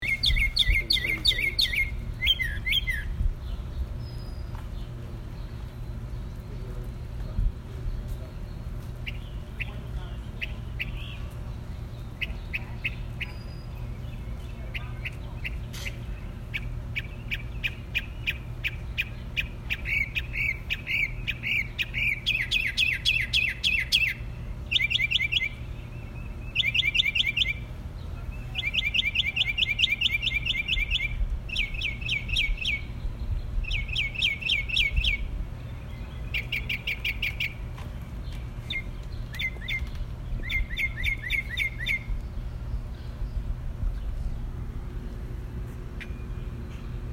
Bird calls in Atlanta